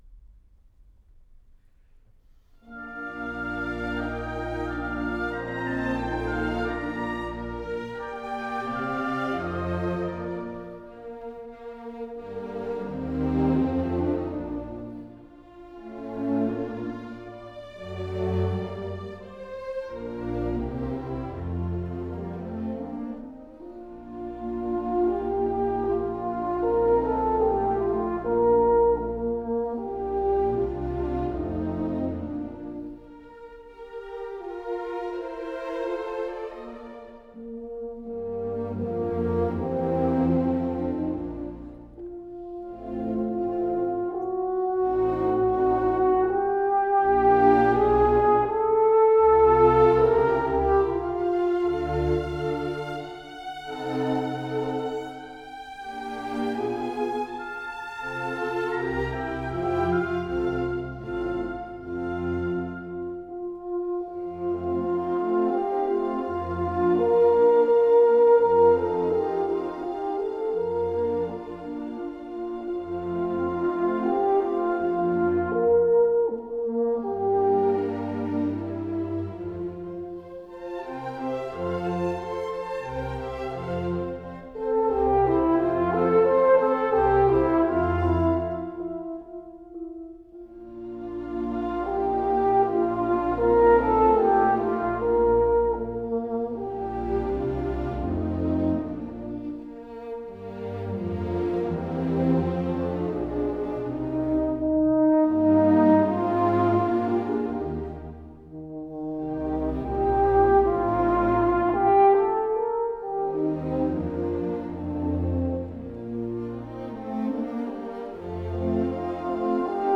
» nhac-khong-loi
Allegro maestoso